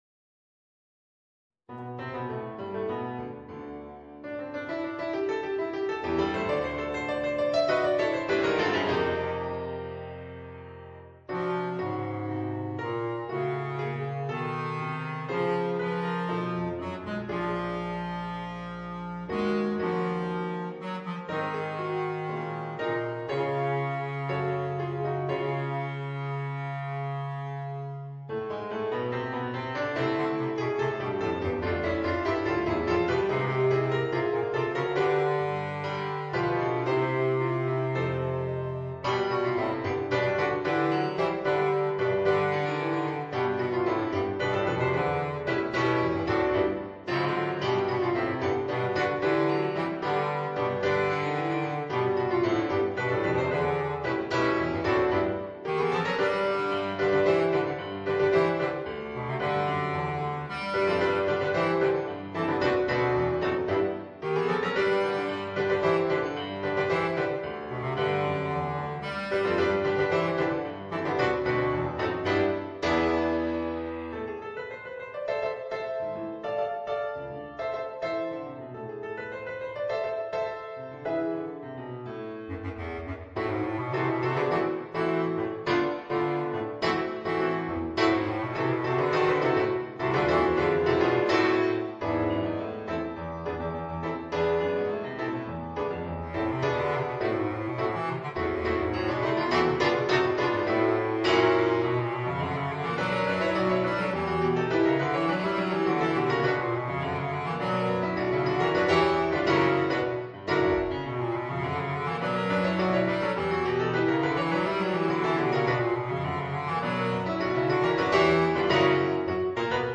Voicing: Bass Clarinet and Piano